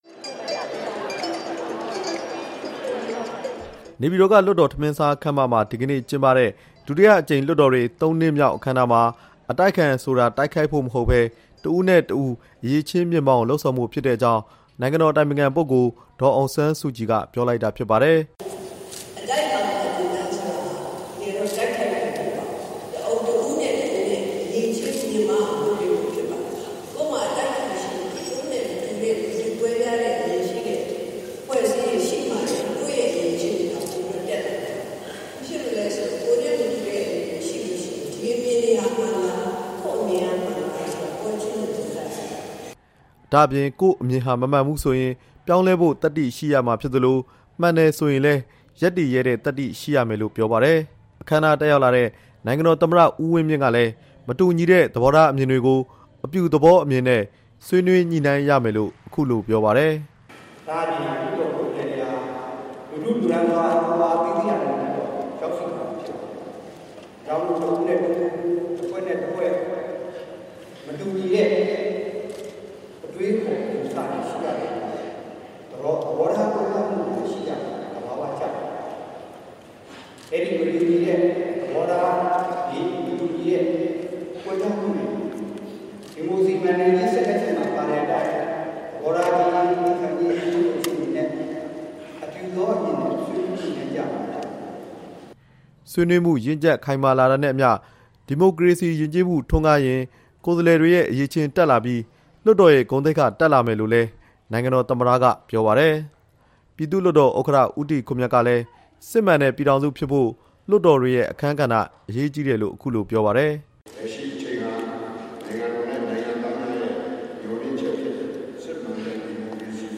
ဒီနေ့ ကျရောက်တဲ့ ဒုတိယအကြိမ် လွှတ်တော် ၃ နှစ်မြောက် အခမ်းအနားမှာ ဒေါ်အောင်ဆန်းစုကြည်က ပြောကြားခဲ့တာဖြစ်ပါတယ်။